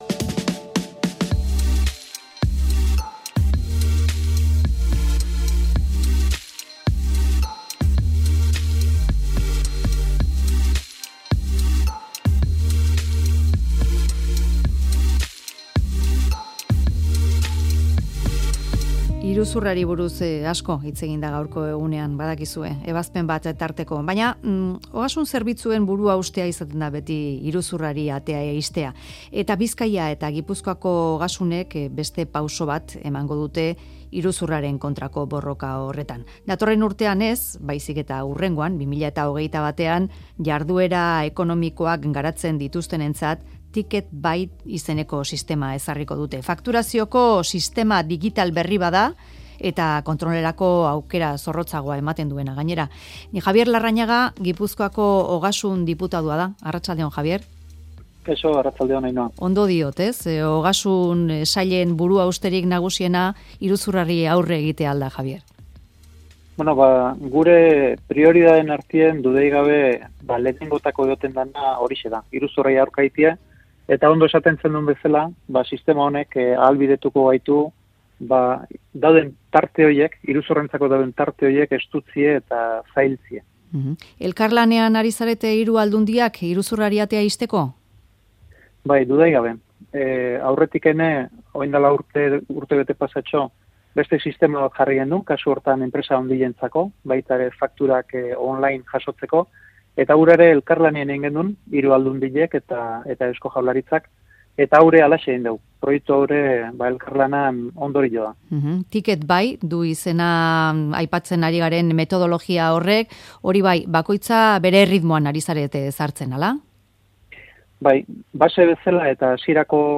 Audioa: Jabier Larrañaga Gipuzkoako Ogasun eta Finantzetako foru diputatua elkarrizketatu dugu Mezularian proiektuaren berri jasotzeko. 2019-12-17.